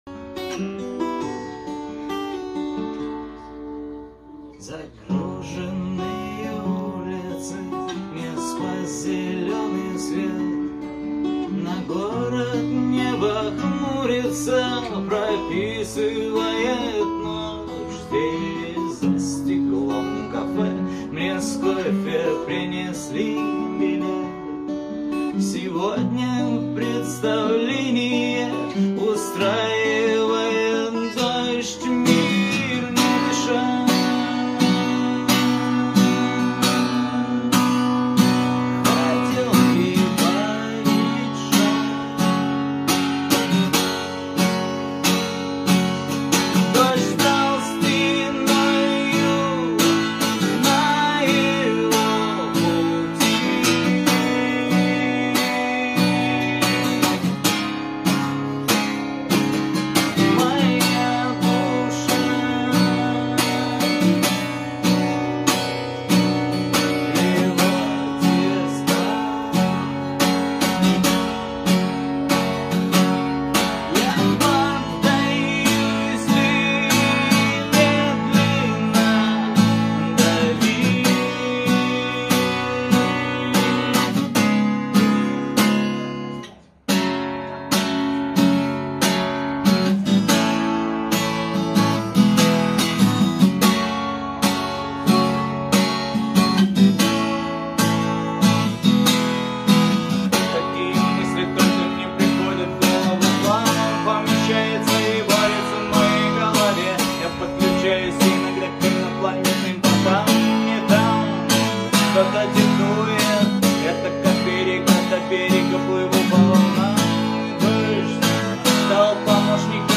авторская песня
под гитару